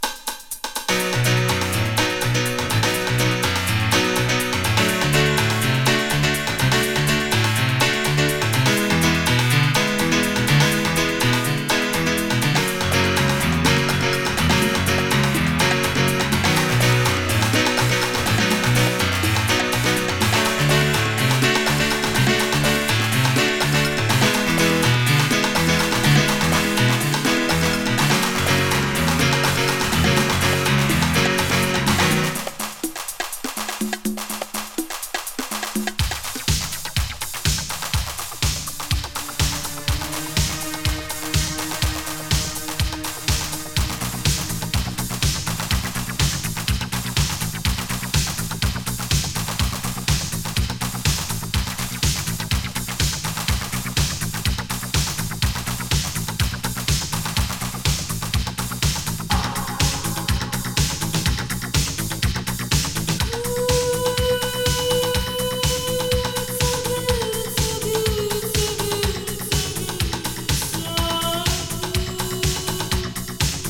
イタロ・ハウス・カヴァー！仰々しいイントロからドウゾ！